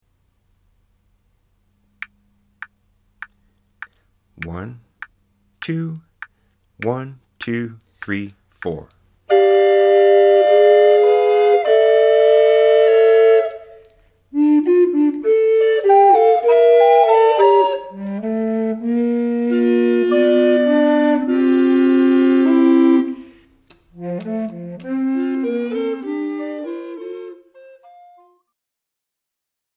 ATB
The bouncy syncopated Alto line’s shape inspired the title.